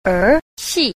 6. 兒戲 – érxì – nhi hí (trò đùa con trẻ)
er_xi.mp3